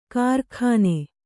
♪ kārkhāne